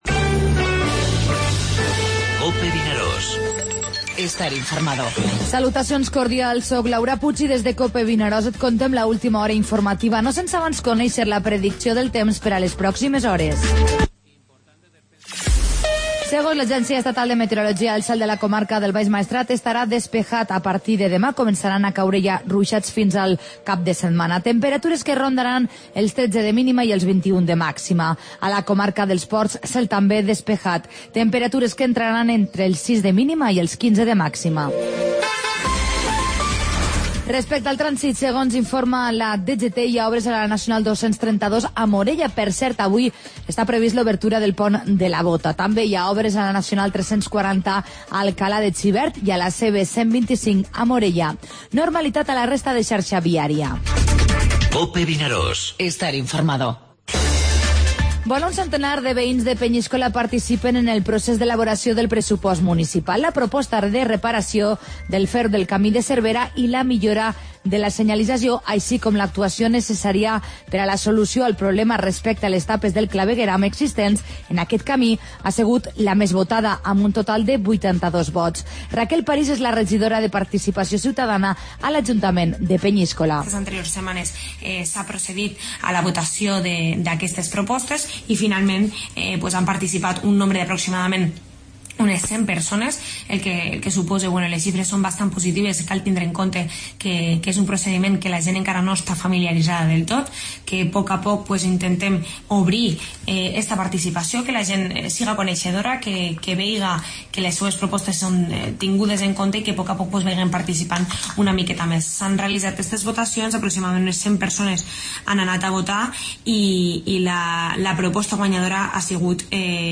Informativo Mediodía COPE al Maestrat (dijous 3 de novembre)